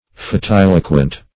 fatiloquent - definition of fatiloquent - synonyms, pronunciation, spelling from Free Dictionary
Search Result for " fatiloquent" : The Collaborative International Dictionary of English v.0.48: Fatiloquent \Fa*til"o*quent\, a. [See Fatiloquist .]